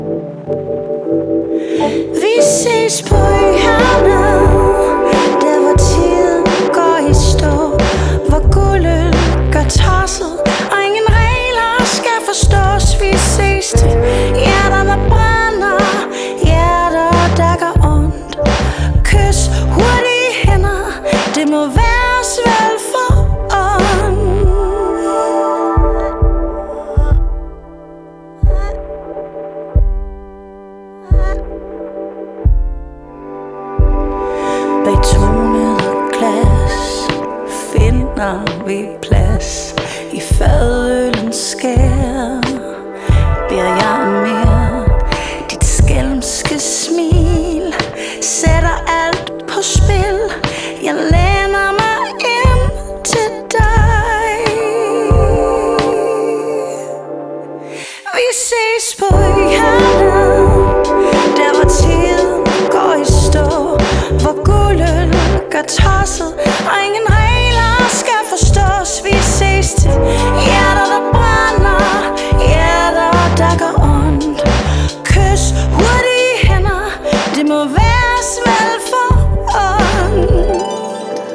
keys
trommer